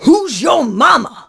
pam_lead_vo_04.wav